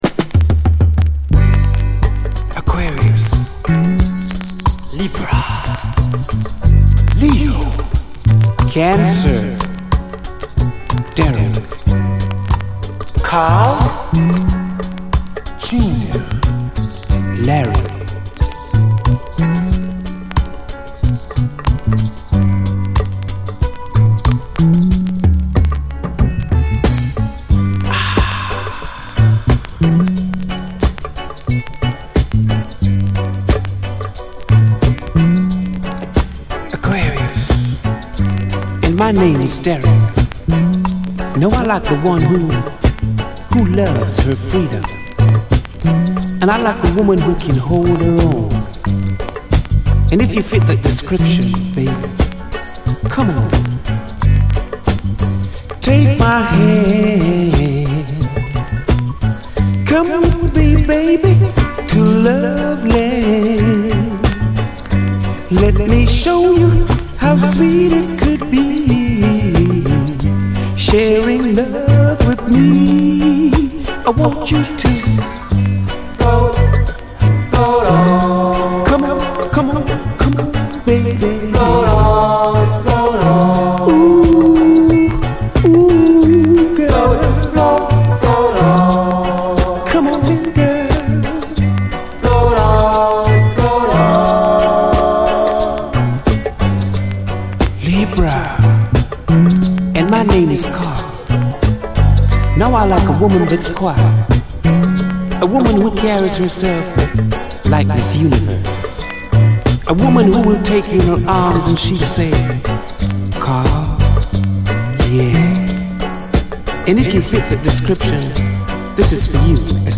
REGGAE, 7INCH
柔らかく包みこむようなグルーヴィーな演奏と甘い語りがタマラないムードを醸し出します！